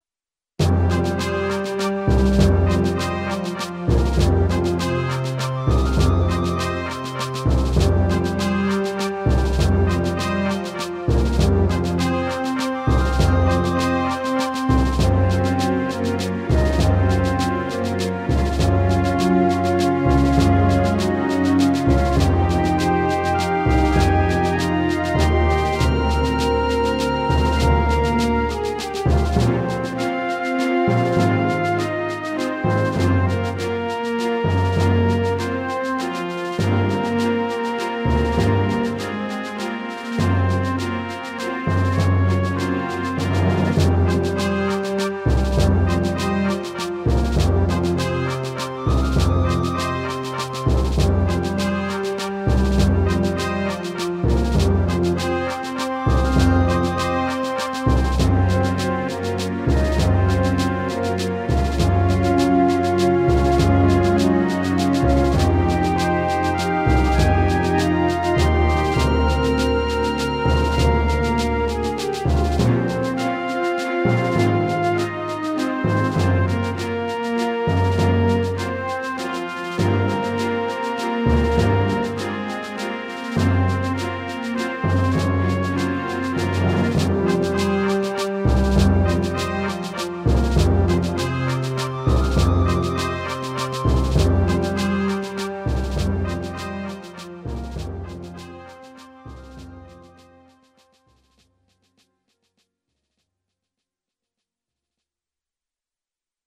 エレクトーンでゲーム音楽を弾きちらすコーナー。